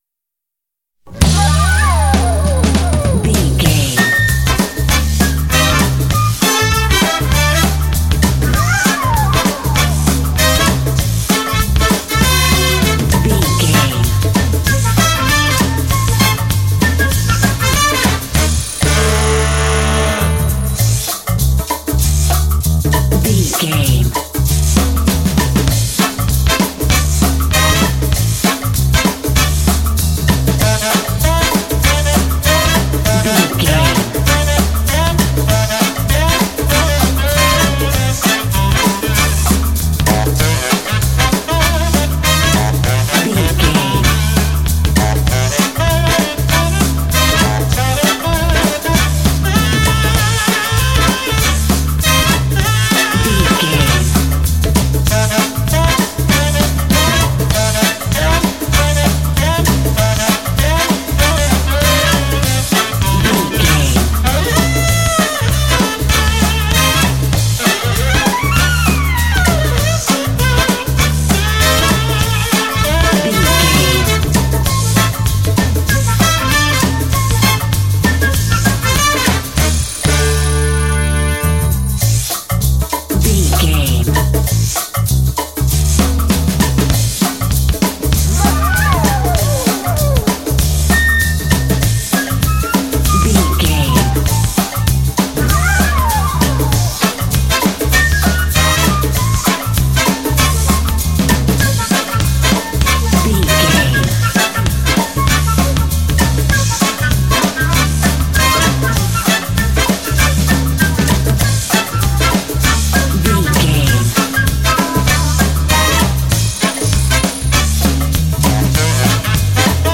Aeolian/Minor
funky
groovy
flute
brass
drums
bass guitar
jazz